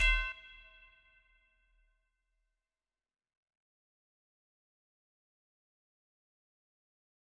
MDMV3 - Hit 21.wav